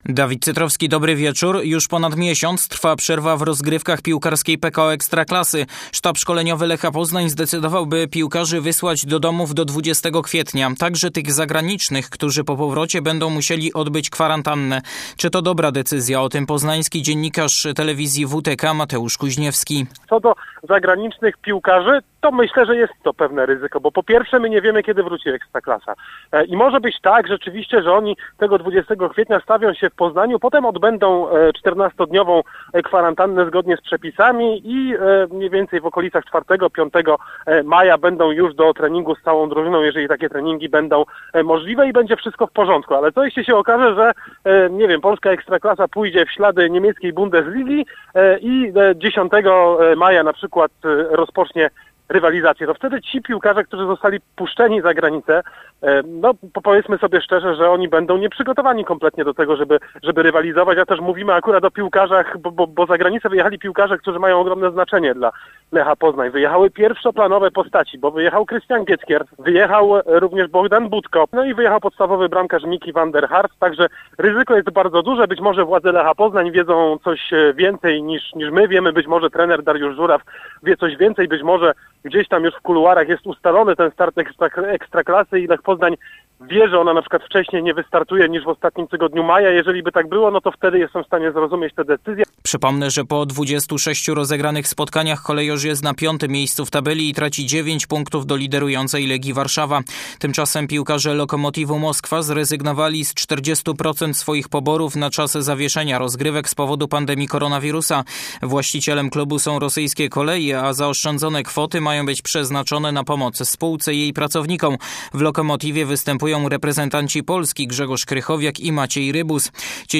10.04. SERWIS SPORTOWY GODZ. 19:05